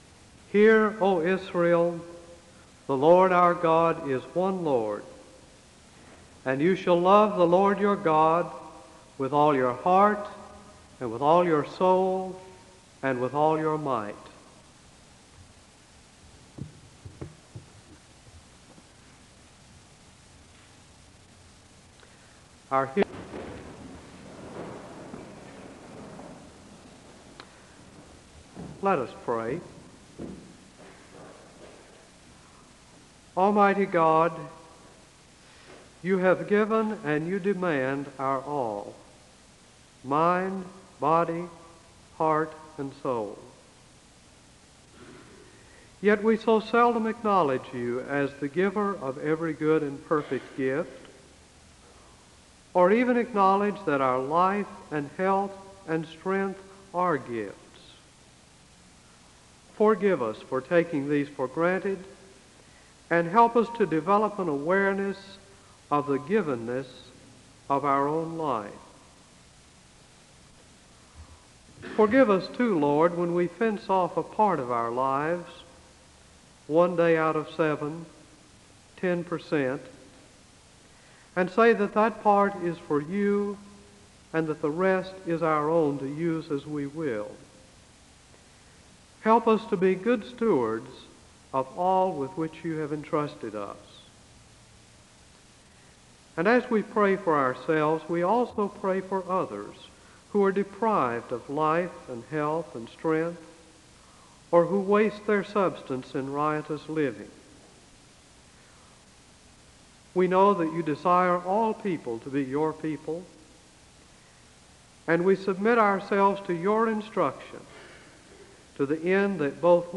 SEBTS Chapel
The service begins with the reading of the Greatest Commandment from Matthew 22 and a word of prayer (00:00-02:12).
The choir sings the anthem (07:51-14:03).
The service ends with a benediction (32:42-33:11).